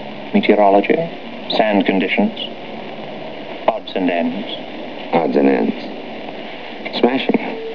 And, of course, an actor who knows how to use his voice as well as Gary Raymond can make even the oddest line sophisticated and memorable.